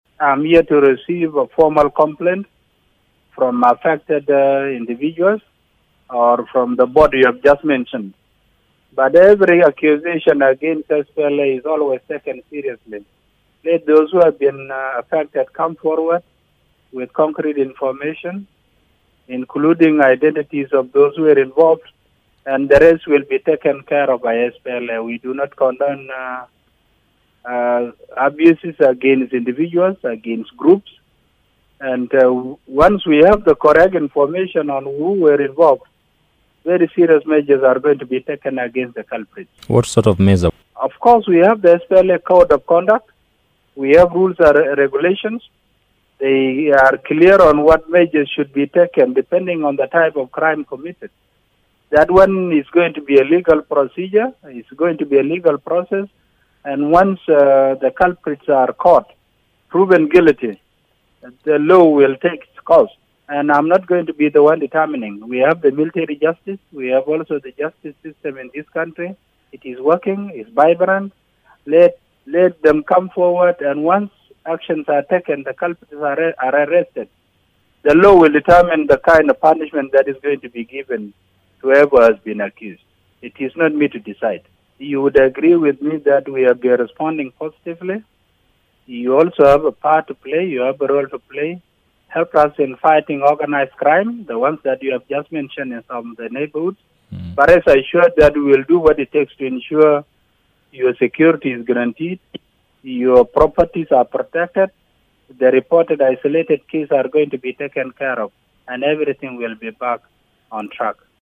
short interview